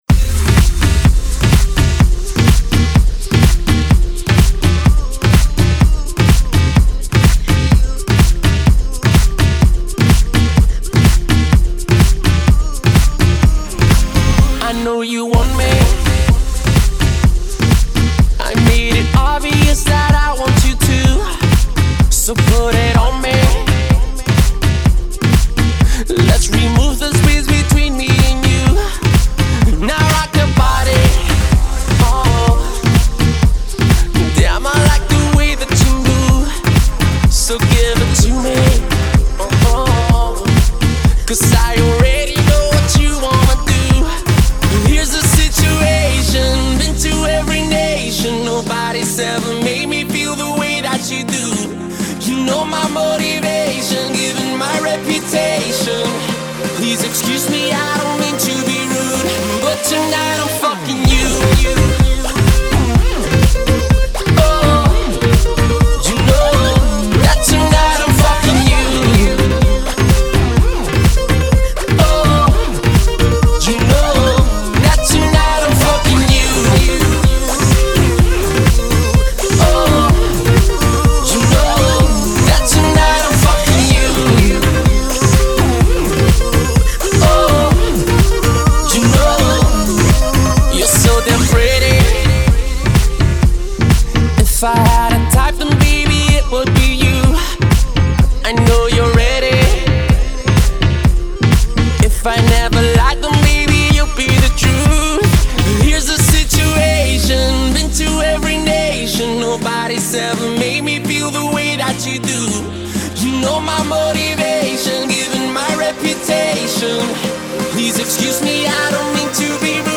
dirty/DJ intro